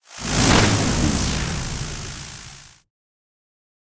spell-impact-lightning-3.ogg